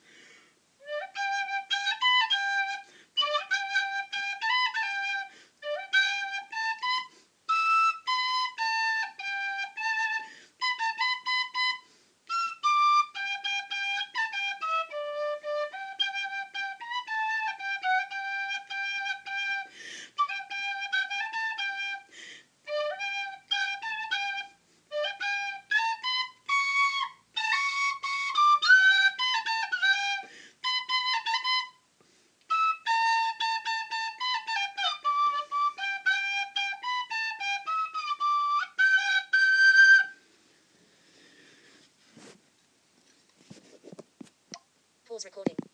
playing tin whistle